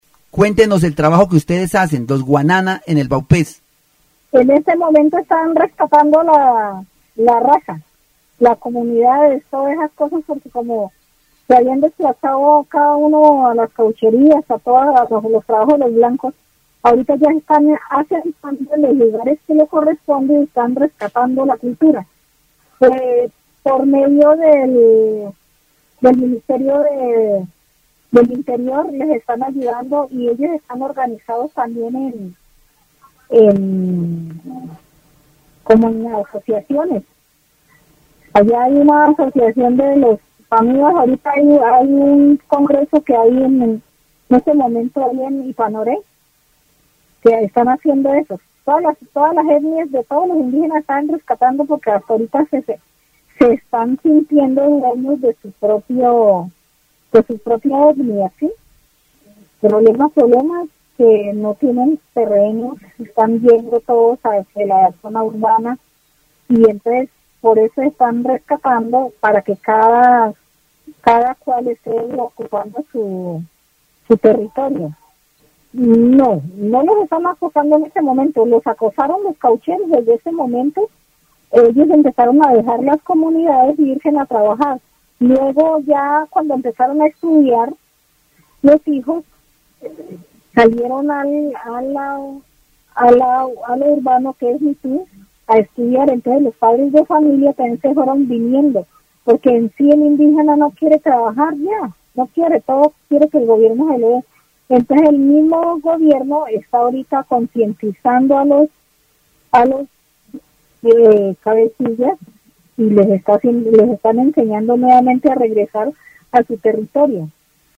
El programa radial presenta una entrevista a un miembro de una comunidad indígena del Vaupés que habla sobre el proceso de rescate de su territorio.
, Vaupés (Región, Colombia) -- Grabaciones sonoras , Miembro de la Comunidad Indígena del Vaupés -- Entrevistas